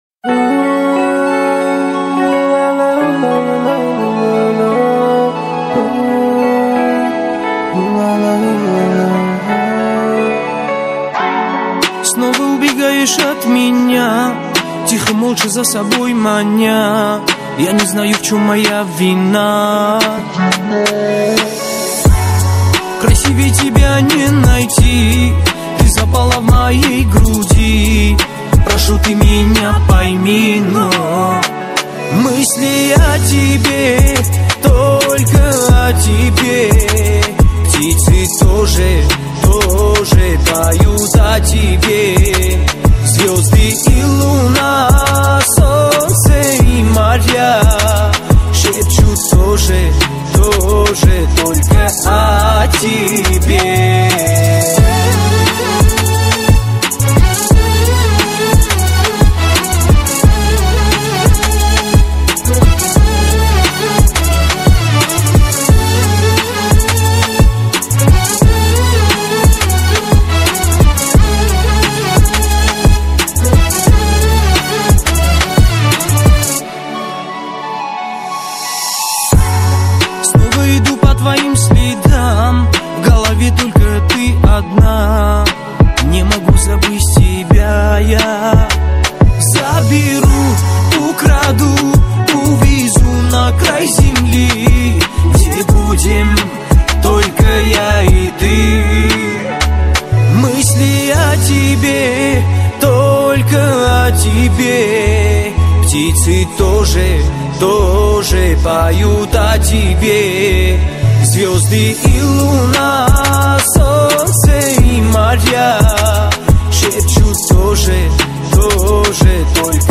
--КАВКАЗСКАЯ_МУЗЫКА_--2020--O_Tебе__2020--_--_--_--_--MP3_128K